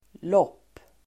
Uttal: [låp:]